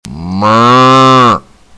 Description: Cow